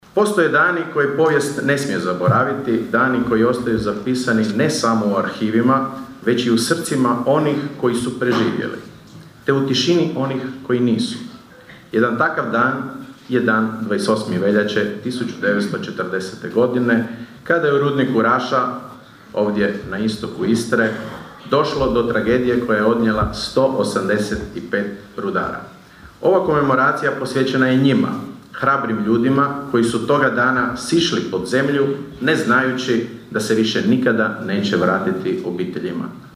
Održani su govori, misa i prigodni program.
U Raši je u subotu, u organizaciji Općine Raša, Turističke zajednice Općine Raša i Zajednice Talijana Giuseppina Martinuzzi, održana komemoracija povodom 86. obljetnice rudarske tragedije u kojoj je poginulo 185 rudara: (